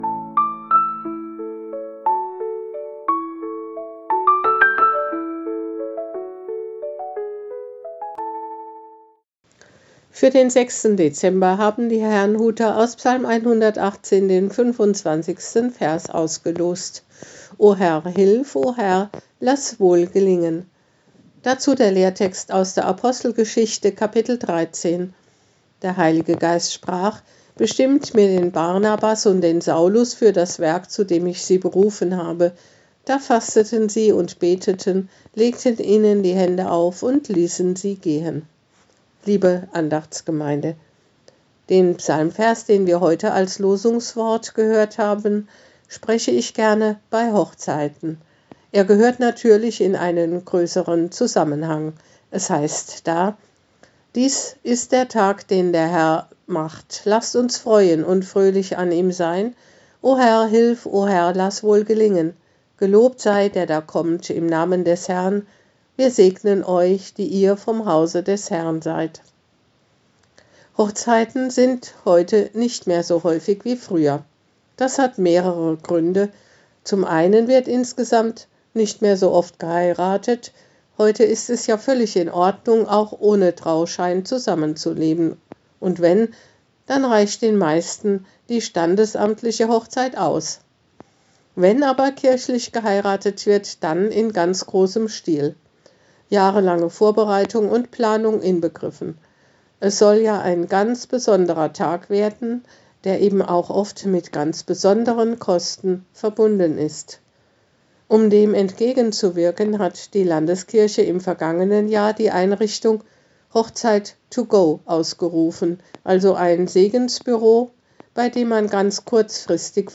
Losungsandacht für Samstag, 06.12.2025
Text und Sprecherin